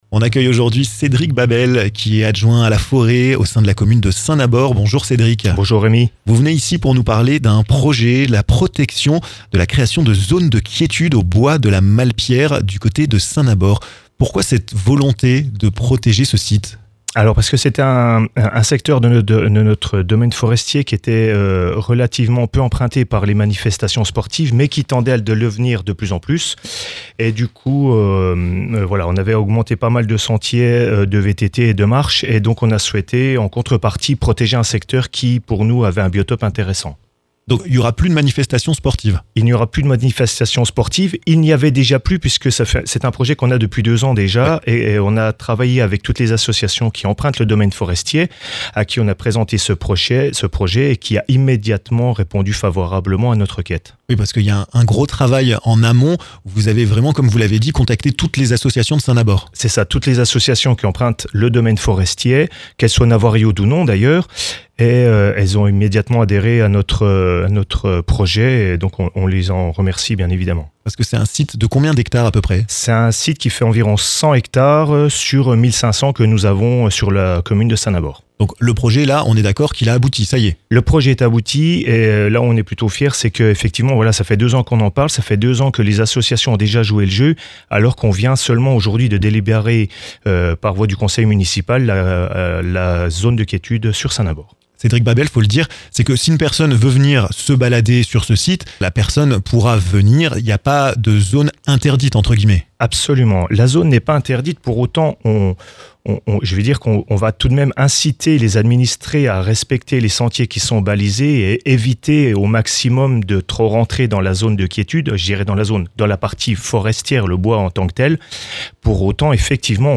Cédric Babel, adjoint à la forêt au sein de la ville de Saint-Nabord, vous parle dans ce podcast de ce projet de création d'une zone de quiétude au bois de la Malpierre.